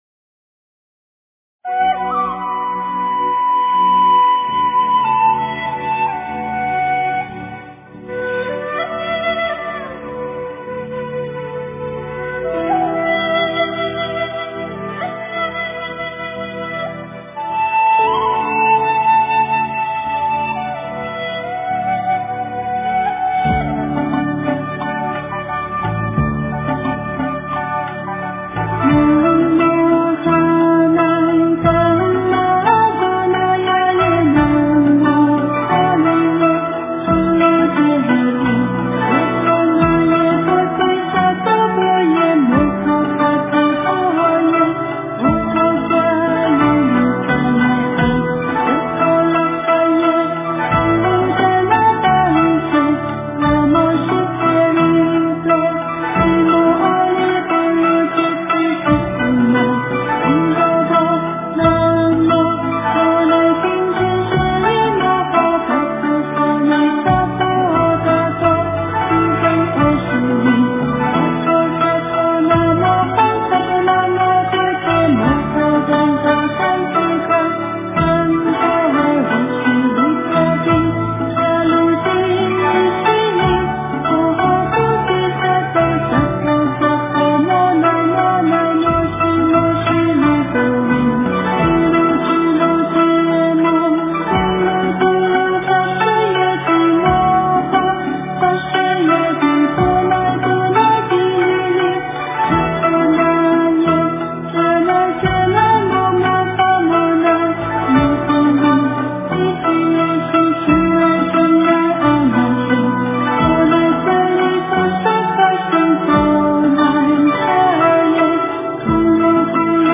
诵经
佛音 诵经 佛教音乐 返回列表 上一篇： 心经 下一篇： 大悲咒-藏传 相关文章 看见爱--Meditation 坐禅 看见爱--Meditation 坐禅...